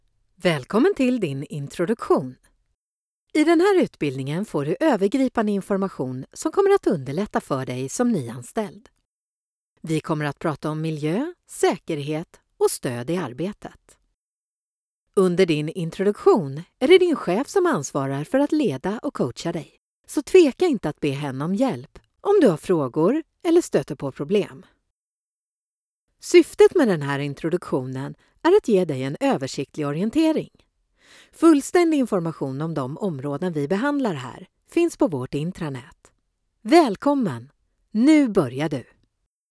Kvinnlig voiceover röst
E-learning